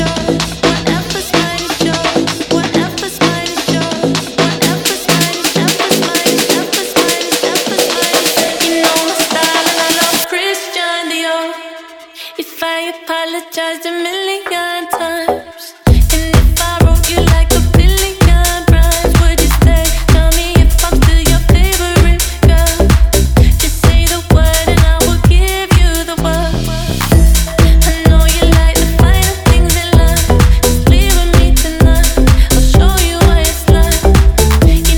Pop
2025-06-06 Жанр: Поп музыка Длительность